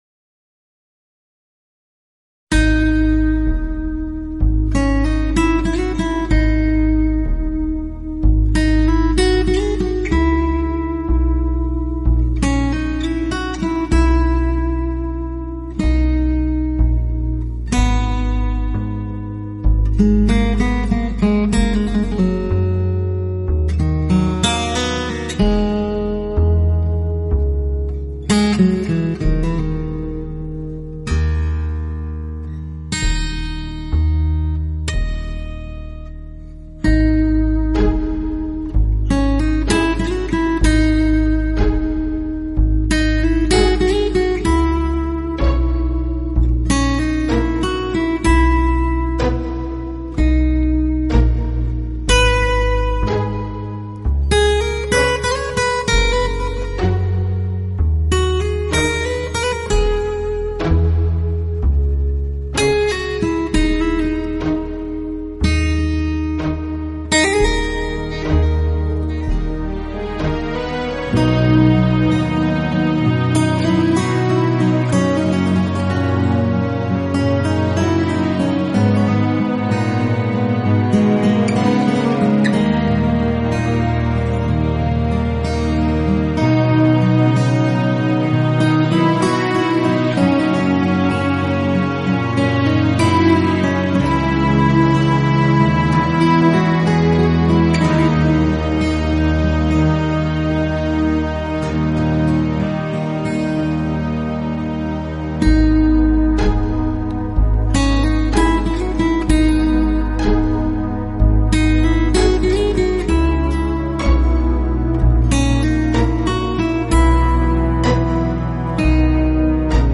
Genre: Folk Rock/Neo-Medieval